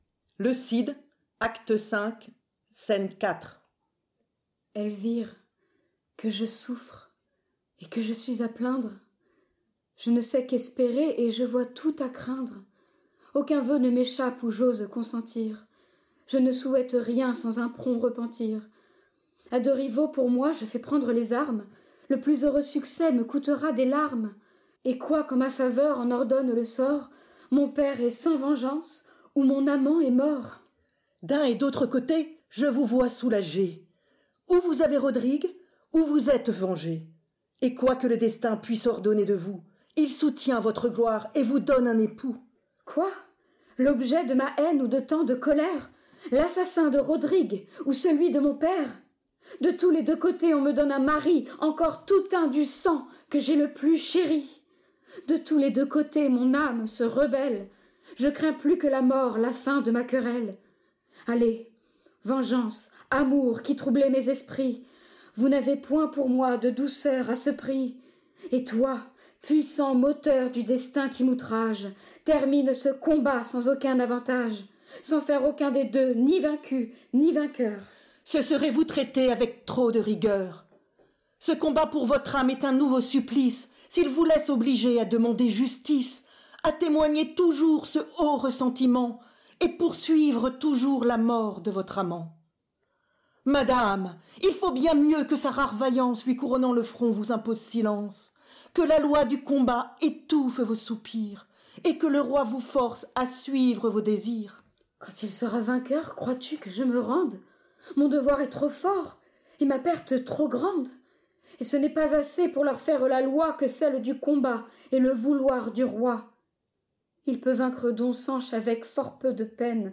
lue par des comédiens.